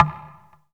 Metro Ambient Perc 1.wav